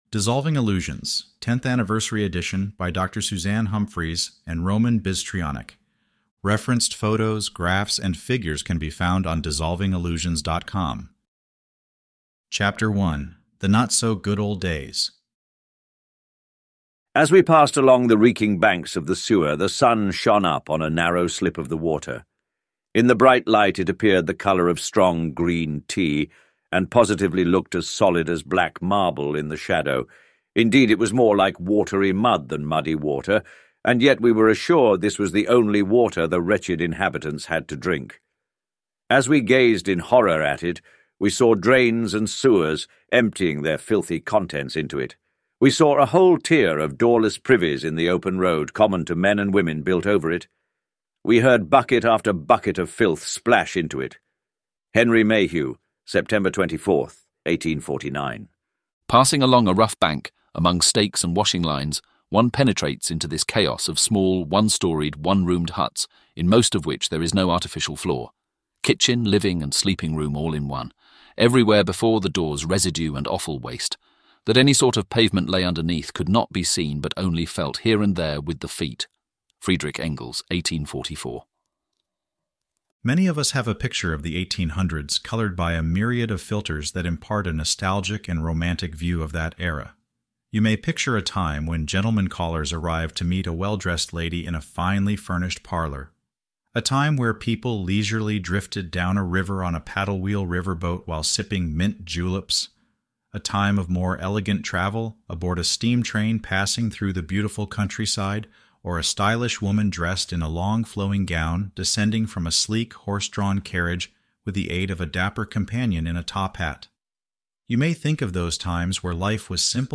Thanks to the incredible outpouring of support from all of you, we now have an audio version of Dissolving Illusions.